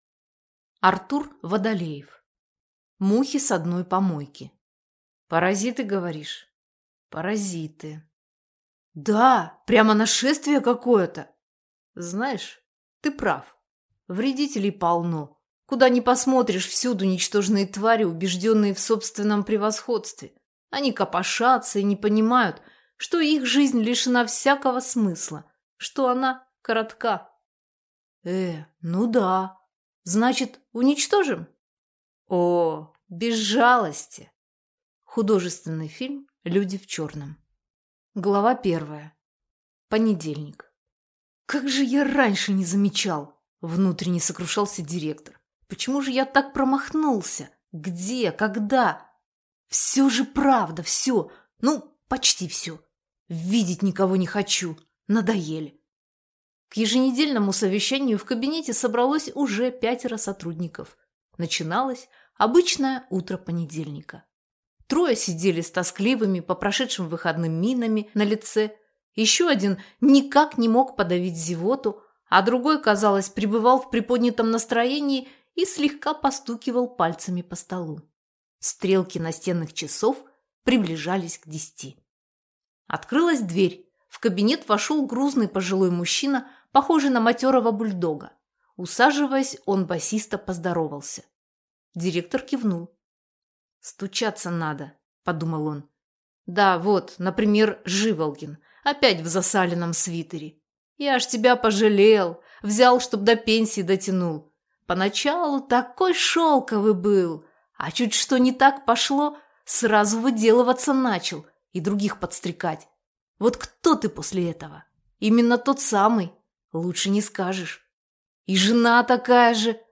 Аудиокнига Мухи с одной помойки | Библиотека аудиокниг
Прослушать и бесплатно скачать фрагмент аудиокниги